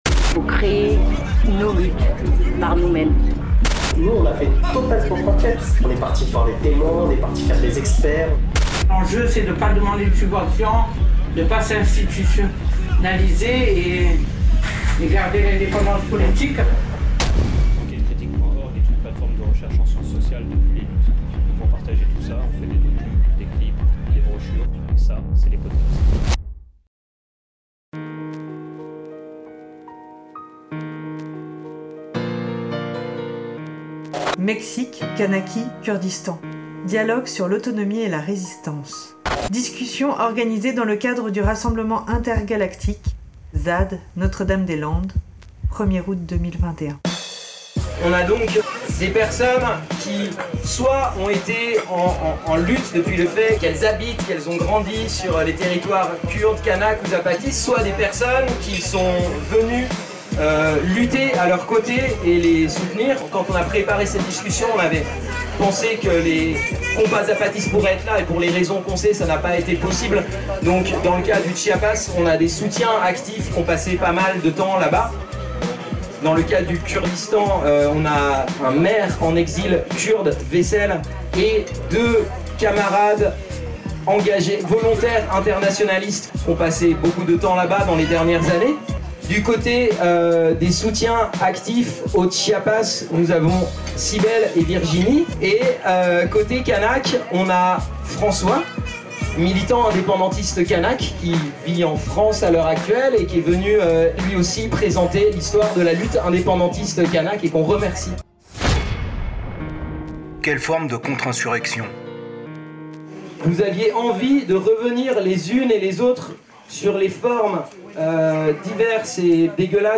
Regards croisés sur les mouvements zapatistes, kurdes et kanak et leurs histoires respectives. Rencontre organisée dans le cadre du rassemblement intergalactique 2021, à Notre-Dame des Landes.